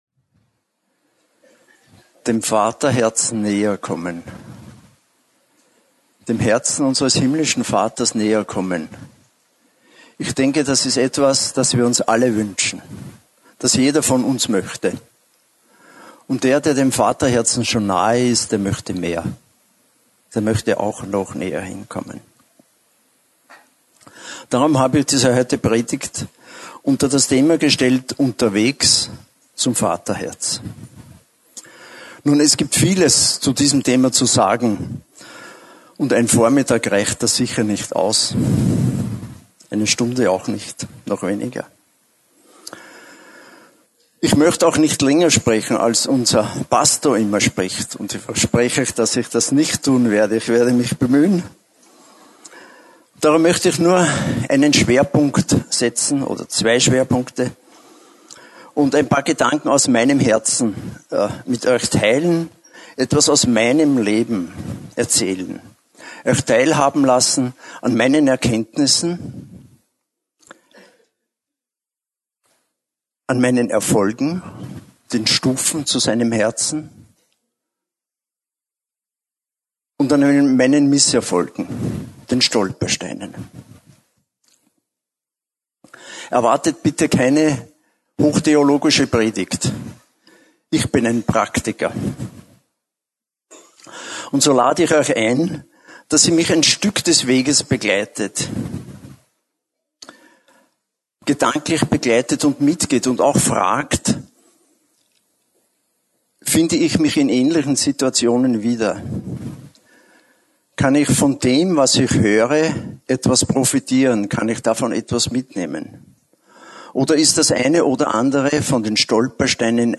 Hier finden Sie das Predigt Archiv für das Jahr 2014.